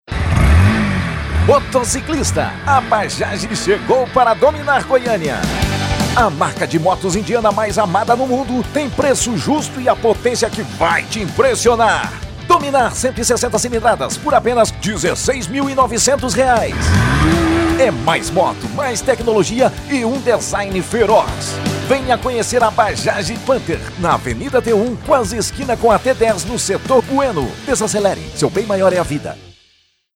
Super Animada: